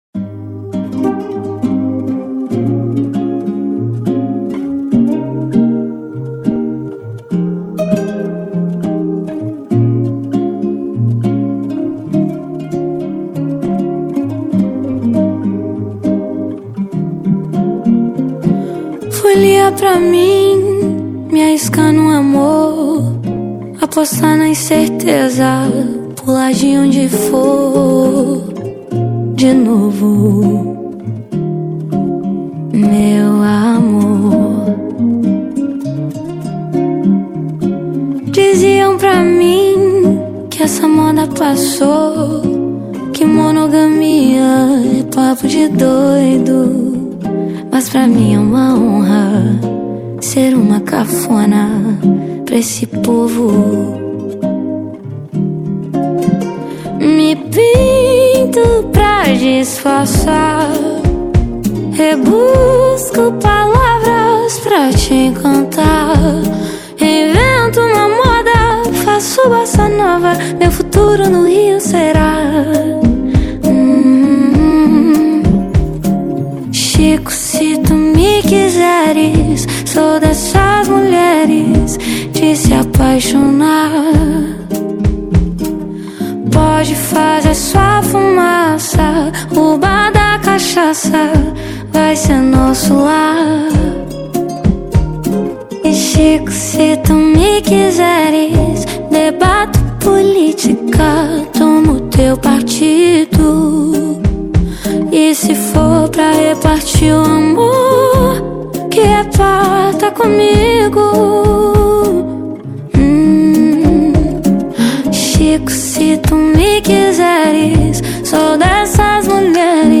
2025-01-31 12:52:07 Gênero: MPB Views